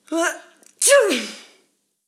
Mujer estornudando
Sonidos: Acciones humanas Sonidos: Voz humana